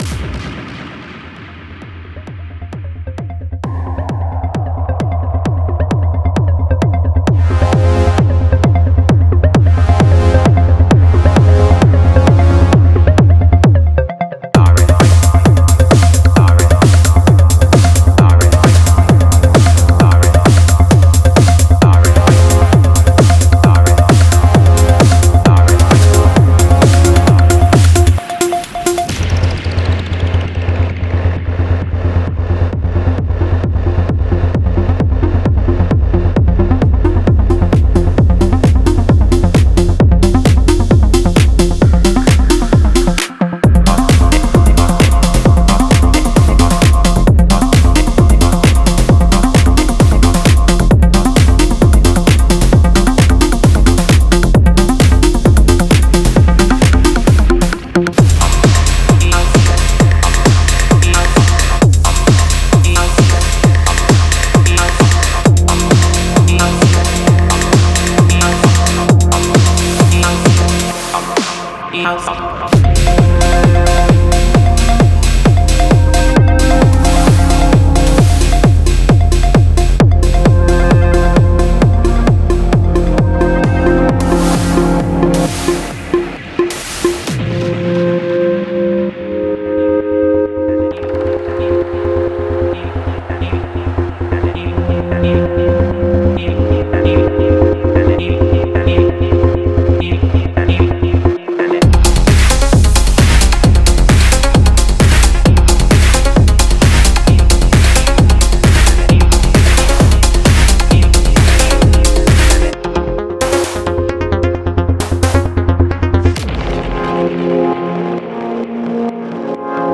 Genre:Techno
ダークでヒプノティックなムードを持つ未来志向のツールキットを提供します。
テクスチャとエフェクトは脈打ちながら削り、うねりを生み出します。
デモサウンドはコチラ↓